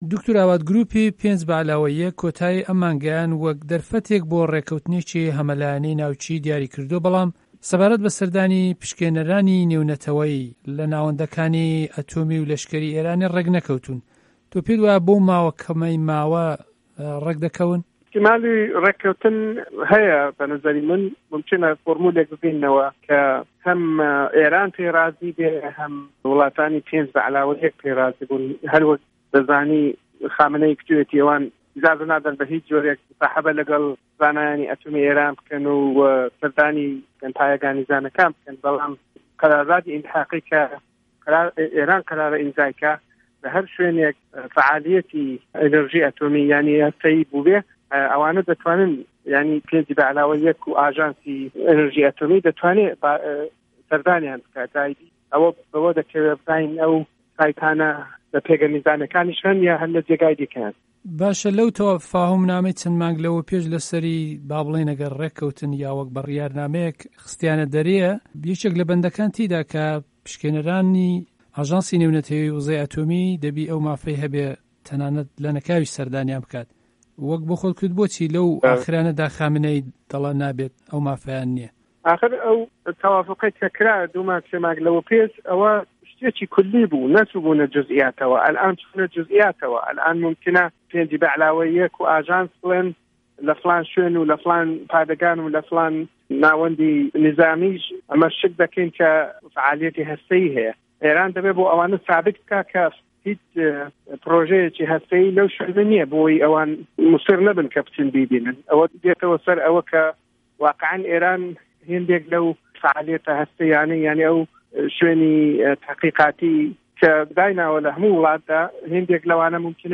وتووێژی ناوکی